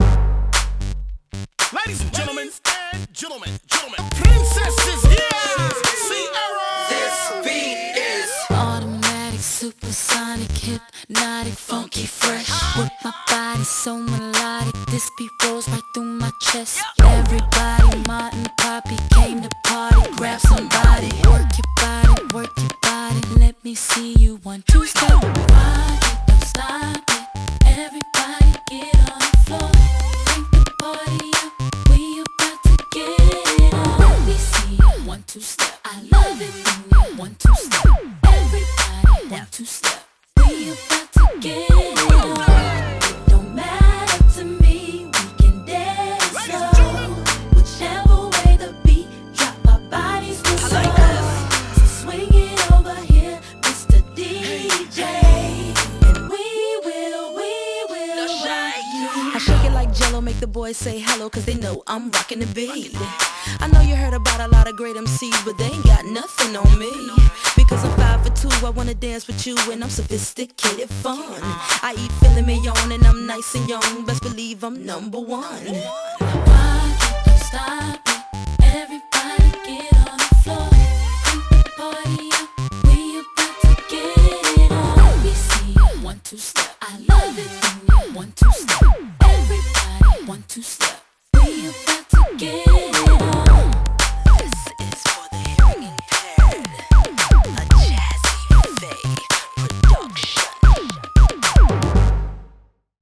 BPM113-113
Audio QualityPerfect (High Quality)
BPM 113 ♥ 1′44″ ♥ Crunk&B